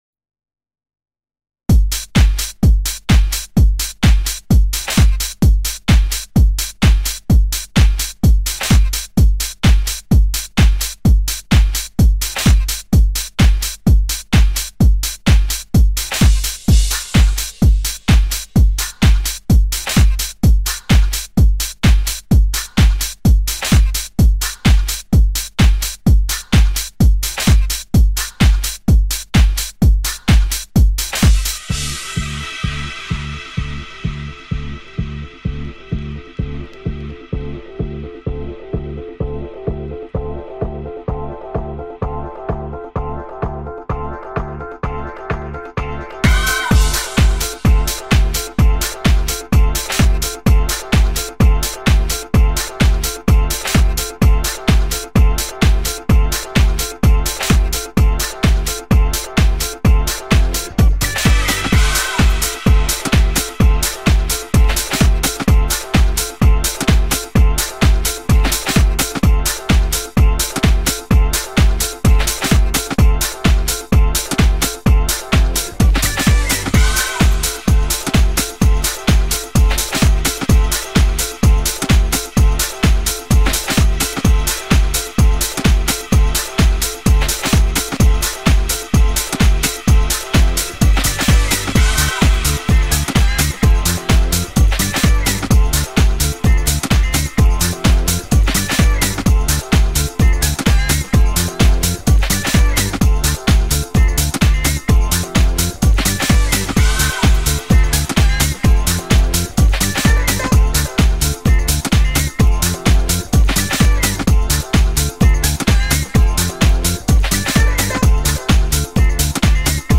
I went for a classic 909 house beat—those ones where the melody repeats to the point that it's annoying (that is, if you pay any attention to it).
techno house
Punchy, love this one!